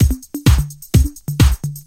Electrohouse Loop 128 BPM (16).wav